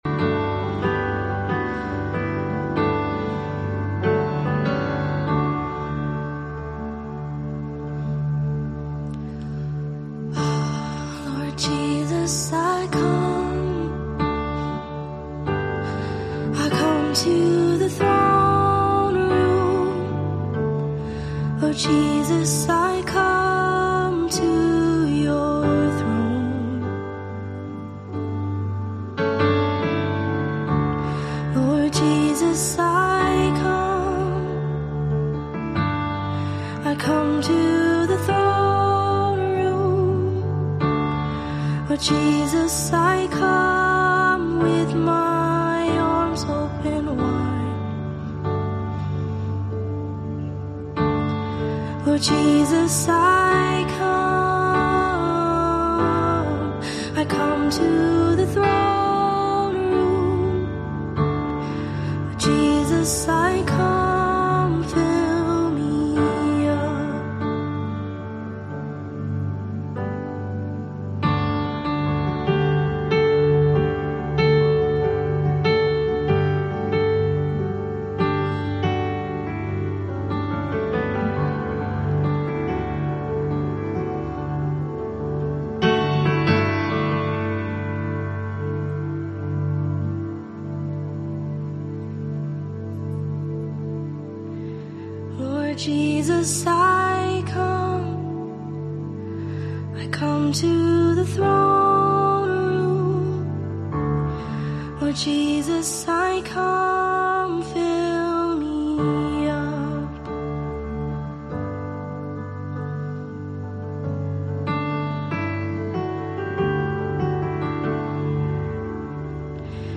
Soaking Prayer and Worship -February 24, 2026 -audio only
Soaking Prayer and Praise